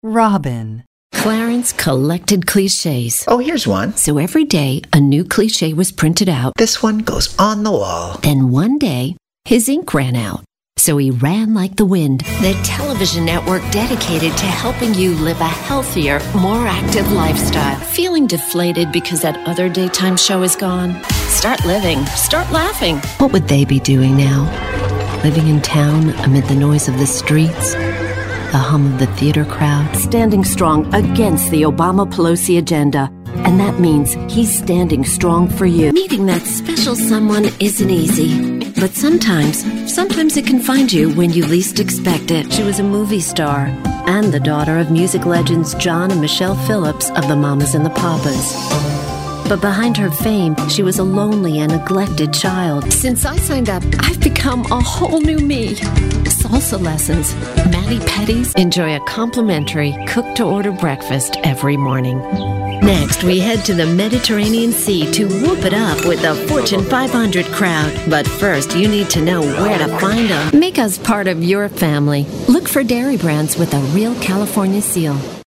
A shining star, whether as a straight announcer or the girl next door.
Showcase Demo
attitude, cool, executive, Midwest, narrative, smooth, technical
anti-announcer, conversational, friendly, genuine, girl-next-door, midlife, real, soccer mom